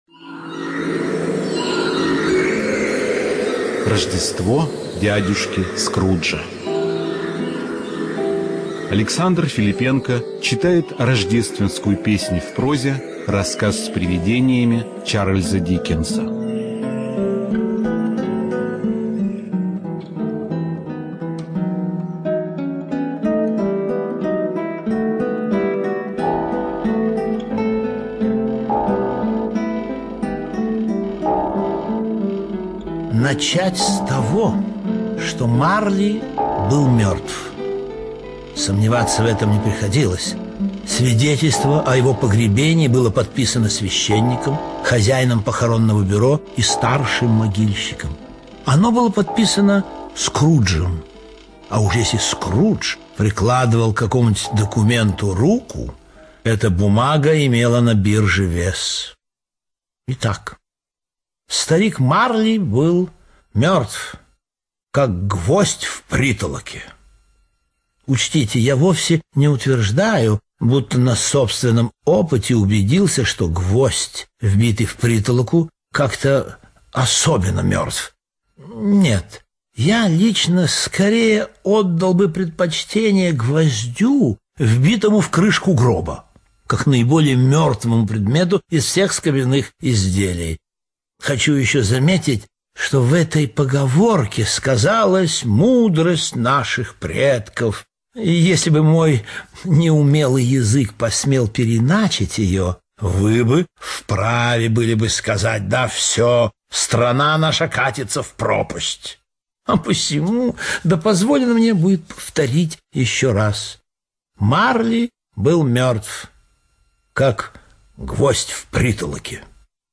ЧитаетФилиппенко А.